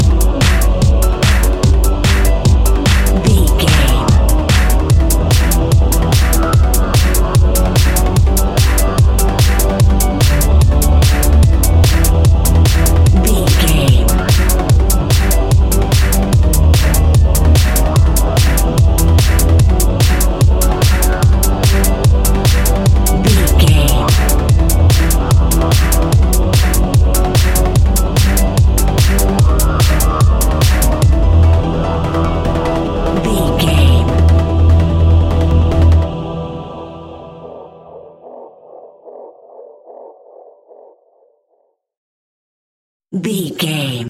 Aeolian/Minor
Fast
meditative
hypnotic
industrial
epic
dark
drum machine
synthesiser
electronic
uptempo
synth leads
synth bass